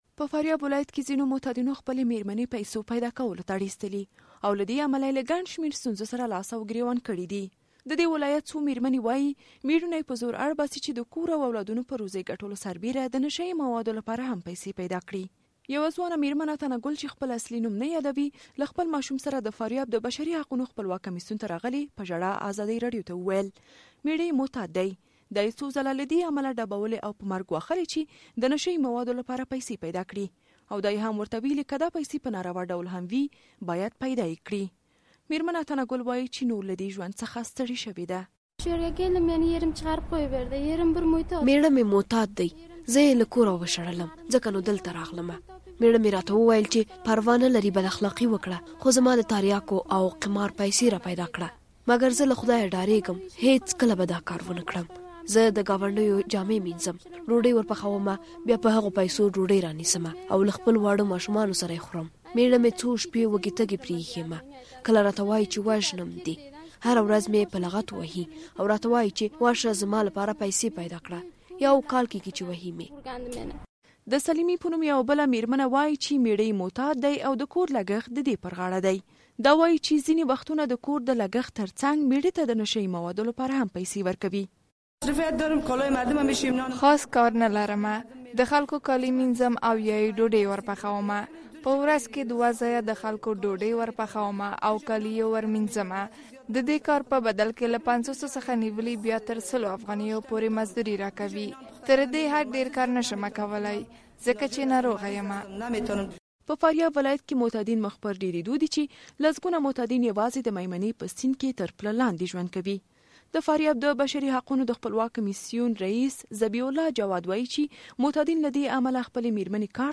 غږیز راپور